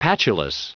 Prononciation du mot patulous en anglais (fichier audio)